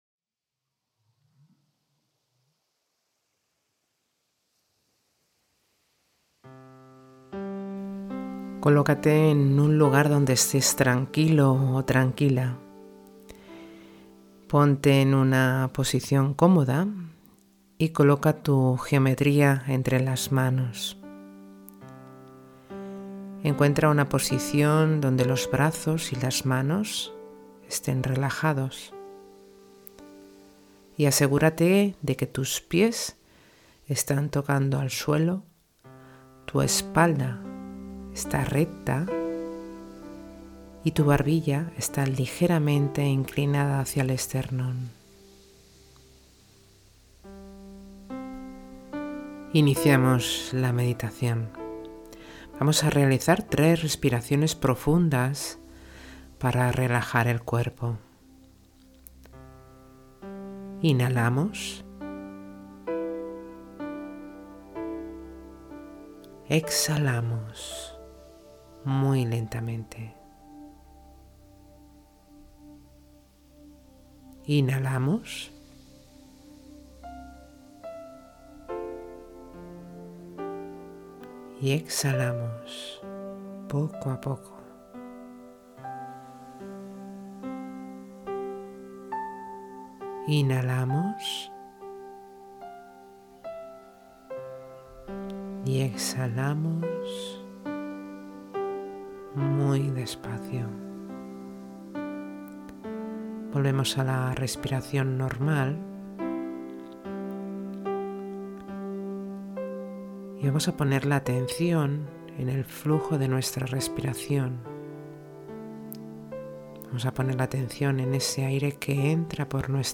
Transmite mucha paz